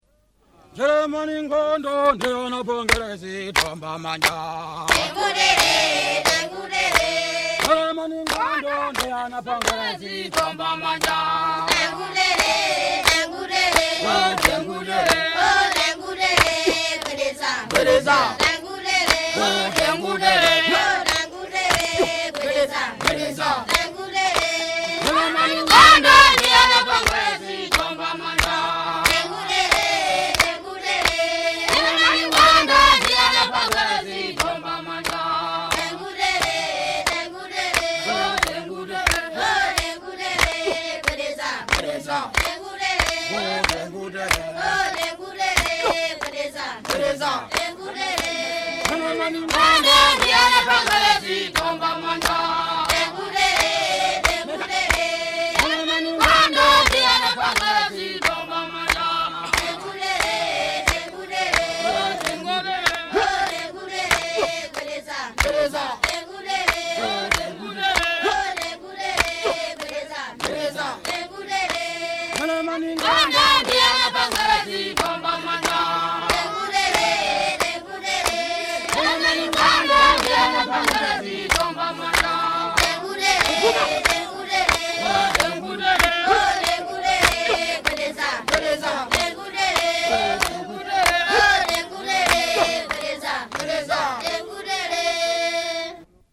Children of Salima Government school
Folk music--Africa
Field recordings
Songs, Chewa
sound recording-musical
Ngona dance with clapping.
96000Hz 24Bit Stereo